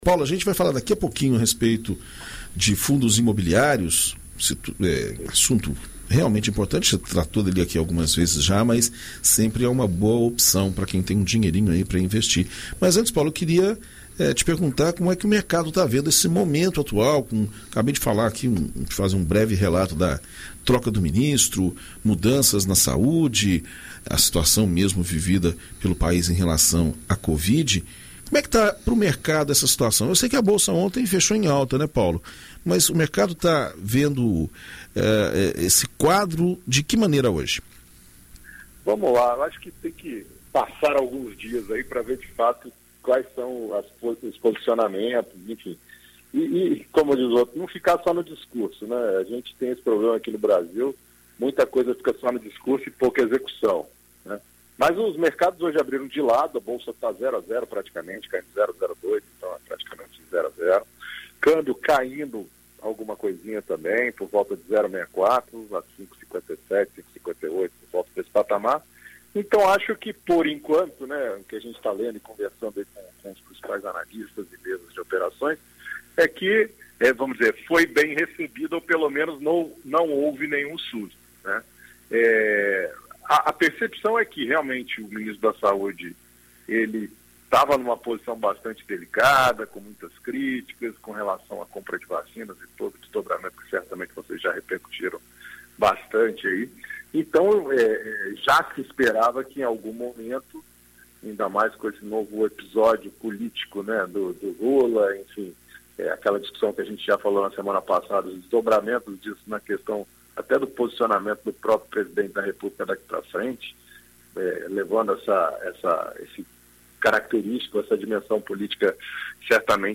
Na coluna Seu Dinheiro desta terça-feira (16), na BandNews FM Espírito Santo